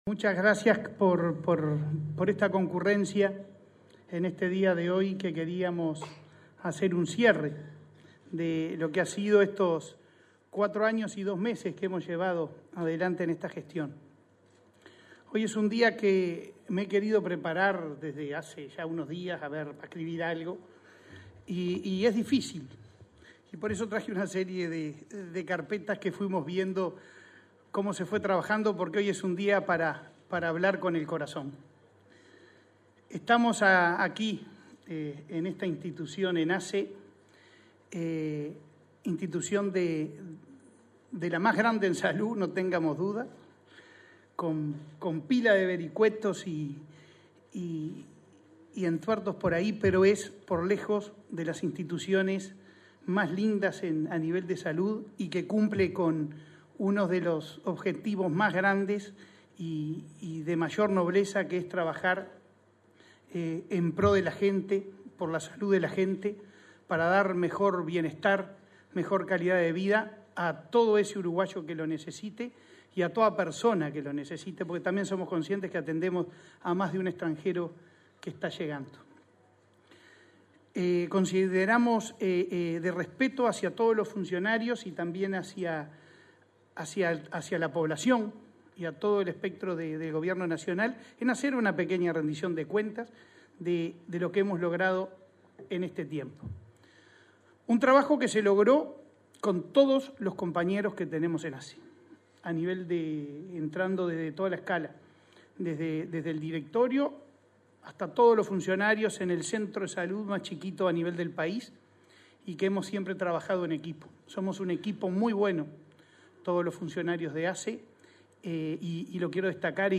Palabras del presidente de ASSE, Leonardo Cipriani
Palabras del presidente de ASSE, Leonardo Cipriani 09/05/2024 Compartir Facebook X Copiar enlace WhatsApp LinkedIn El presidente de la Administración de los Servicios de Salud del Estado (ASSE), Leonardo Cipriani, realizó, este 9 de mayo, el acto de cierre de su gestión al frente del prestador público de salud.